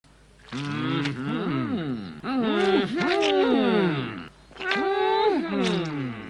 tintin_dupon_hummm_hum.mp3